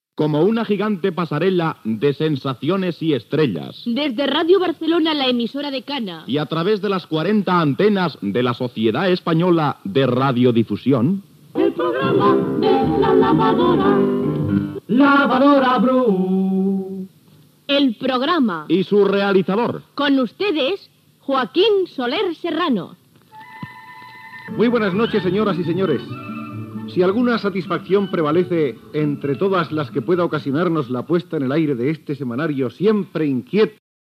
Careta del programa i salutació inicial